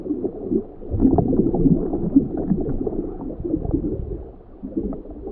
mcl_ambience - Ambient sounds for mcl2.
scuba1bubbles.ogg